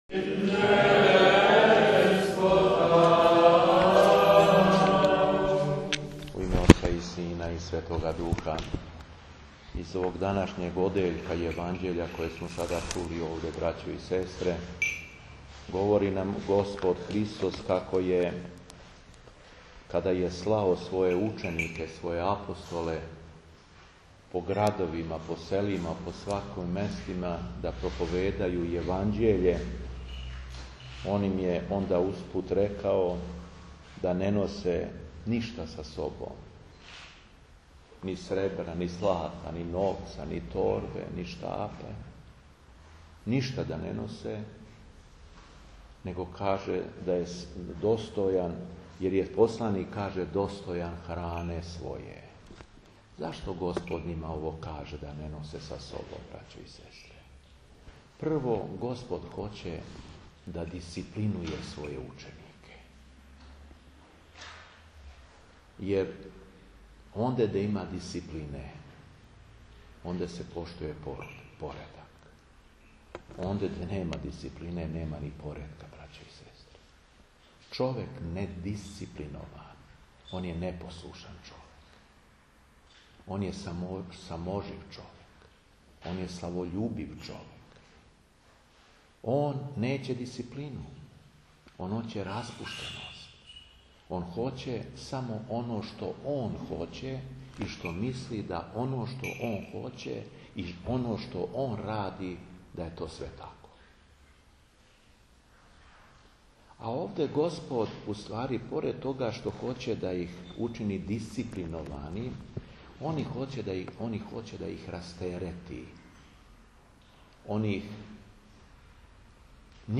ЛИТУРГИЈА У ХРАМУ СВЕТОГ ДИМИТРИЈА У КРАГУЈЕВЦУ - Епархија Шумадијска
Беседа Епископа шумадијског Г. Јована